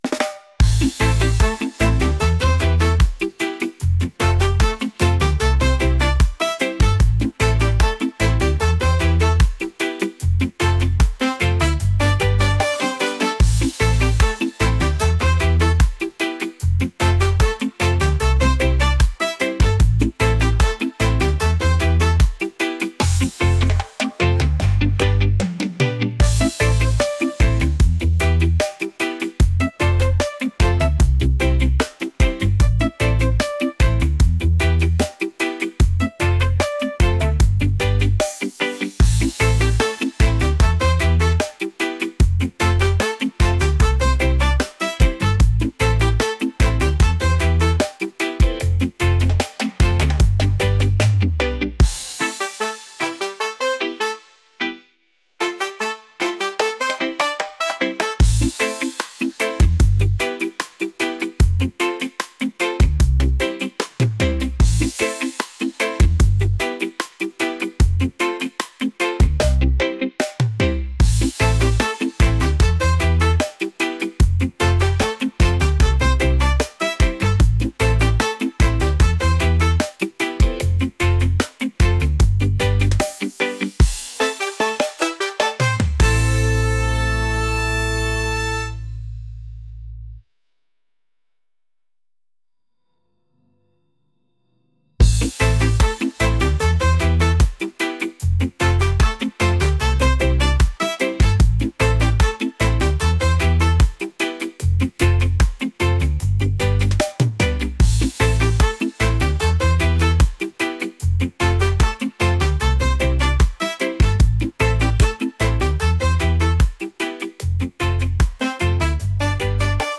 reggae | catchy | upbeat